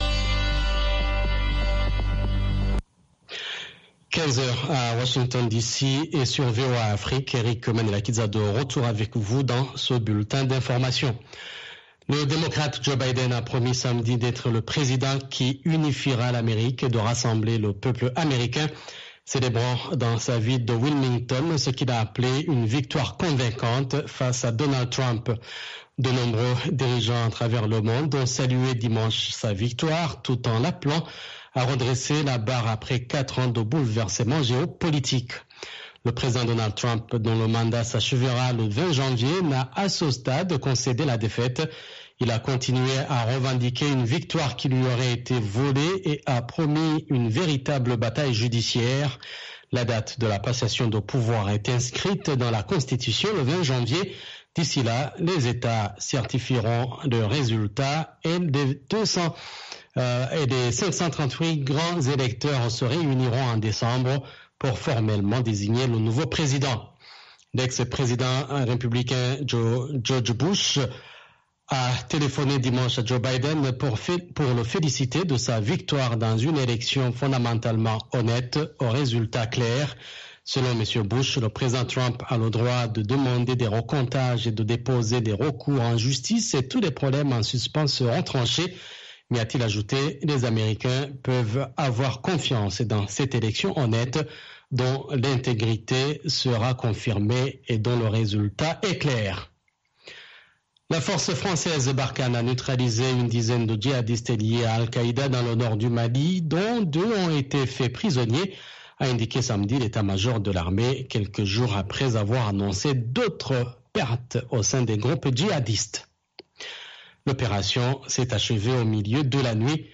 Blues and Jazz Program Contactez nous sur facebook